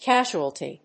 音節ca・su・al・ty 発音記号・読み方
/kˈæʒuəlti(米国英語), ˈkaʒ(ʊ)əlti(英国英語)/